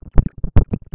Nota realizada al Pte. Jorge Batlle en el L.A.T.U., con motivo de la